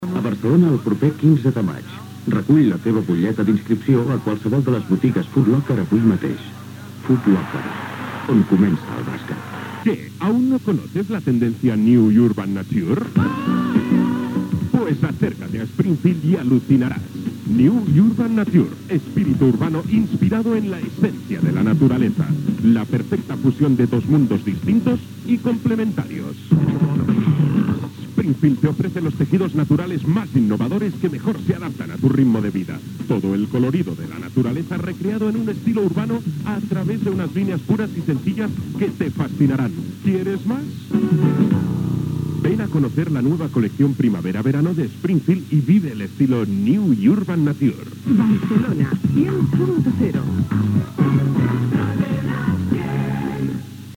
Publicitat i indicatiu